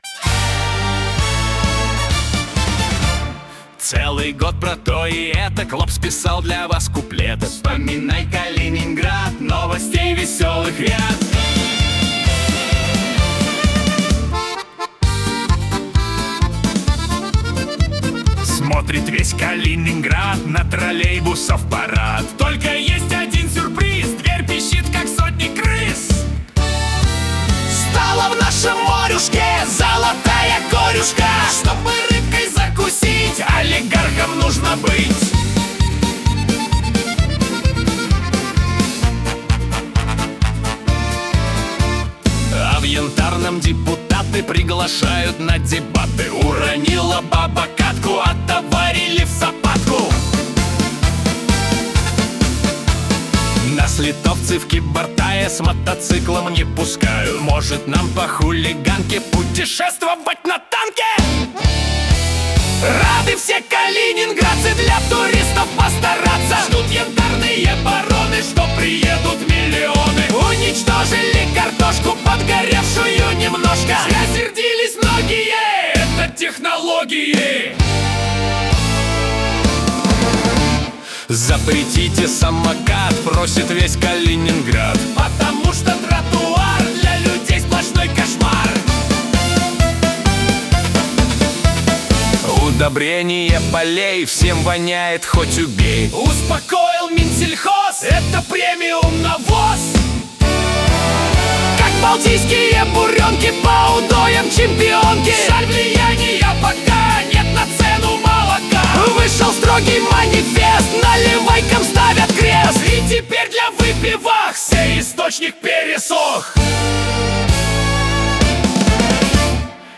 Частушки о главных и важных событиях 2025-го